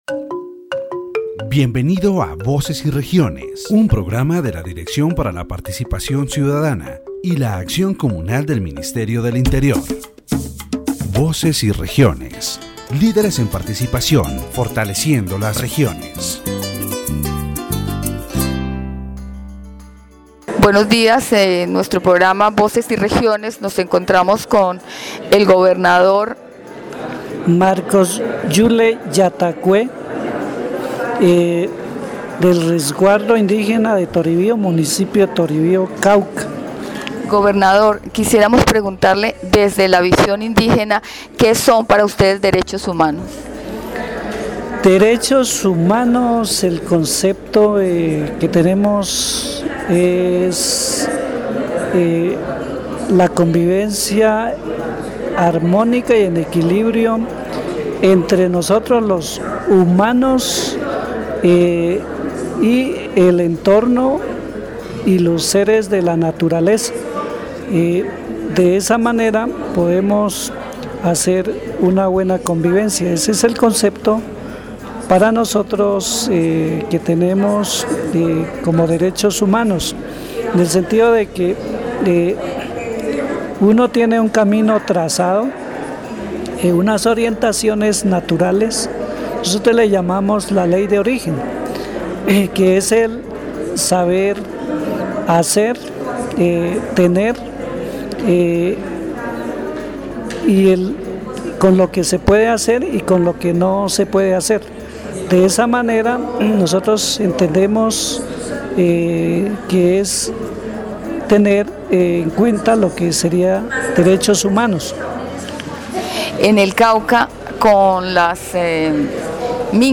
In this section of the Voces y Regiones program, the governor of the Toribío reservation in Cauca is interviewed, sharing his views on the human rights situation in Colombia and highlighting the impact of the armed conflict on his community. He addresses the need for the demilitarization of the territory as a means to reduce violence and promote local development. The governor also denounces the forced recruitment of young people by guerrilla groups, which has hindered the progress of the Indigenous community.